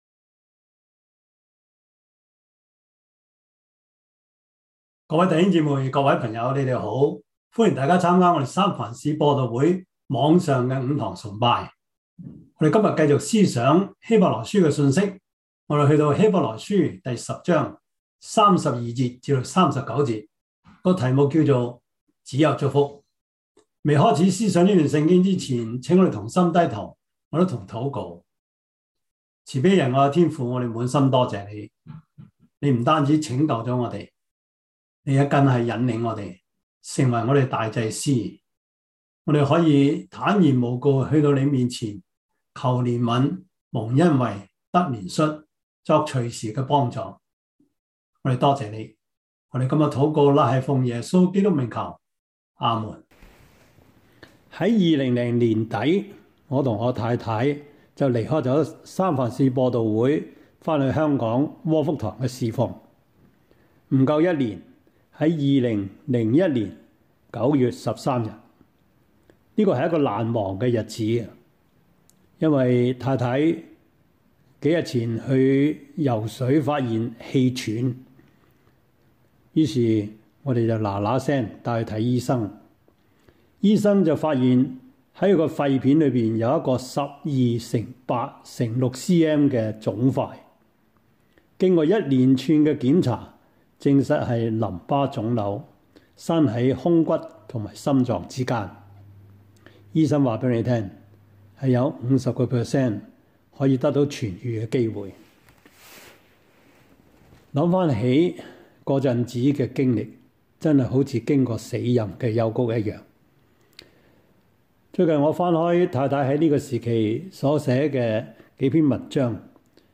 Service Type: 主日崇拜
Topics: 主日證道 « 天父的呼喚 第二十課: 和合本聖經與中國教會 »